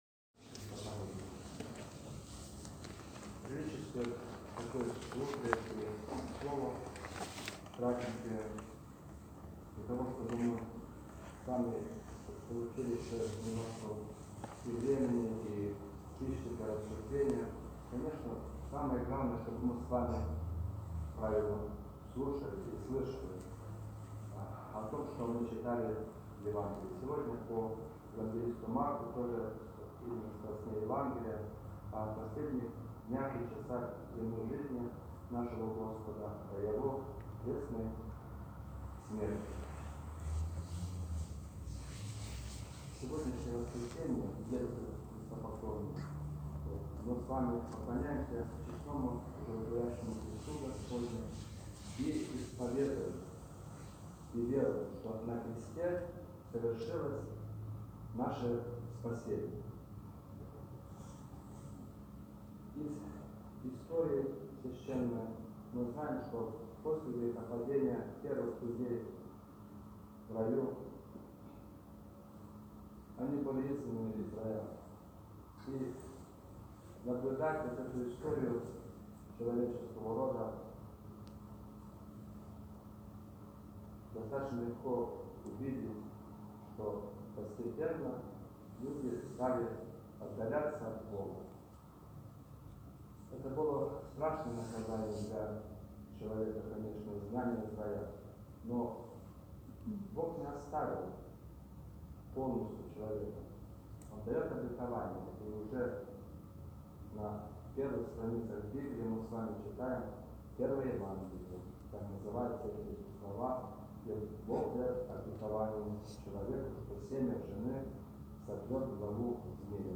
Проповедь настоятеля нашего храма после вечерни с чином Пассии в Неделю 3-ю Великого Поста